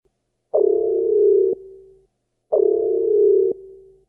return of the station alarms
voybluealert.ogg